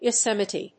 /joˈsɛmɪti(米国英語), jəʊˈsemɪti:(英国英語)/